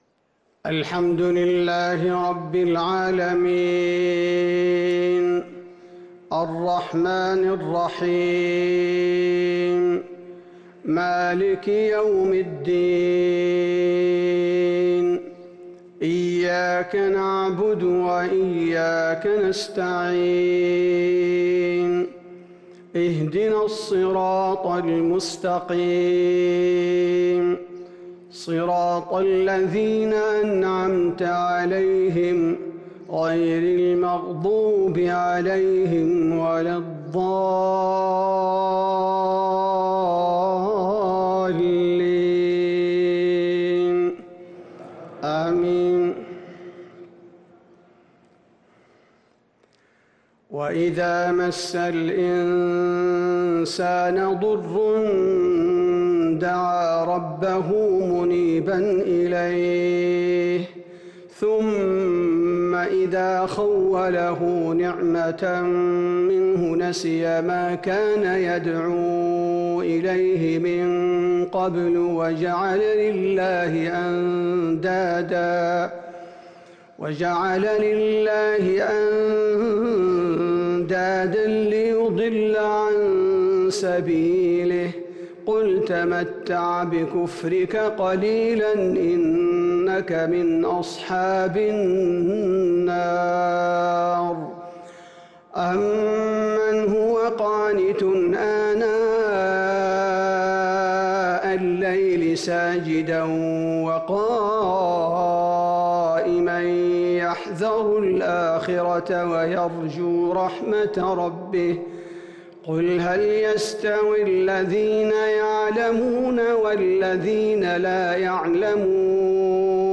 صلاة الفجر من سورة الزمر 20 رمضان 1442هـ |2-5-2021 Fajr prayer from Surat Al-Zumar > 1442 🕌 > الفروض - تلاوات الحرمين